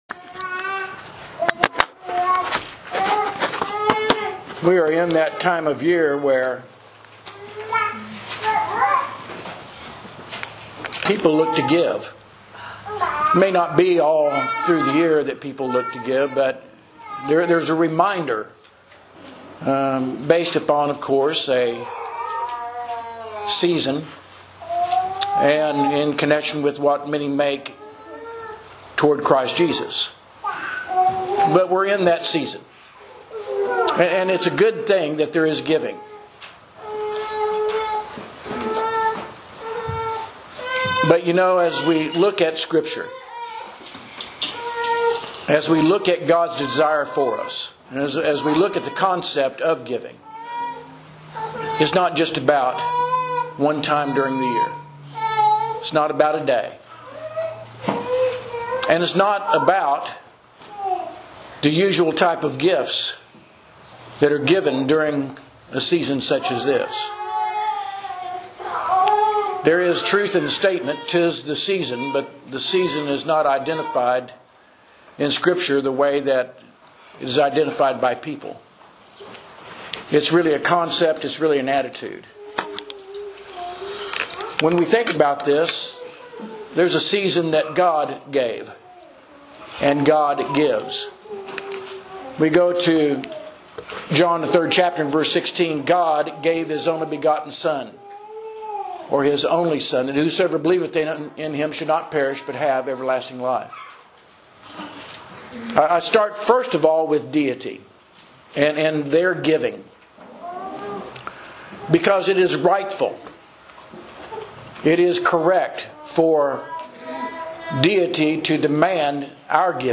Sermons – Page 9 – South Loop church of Christ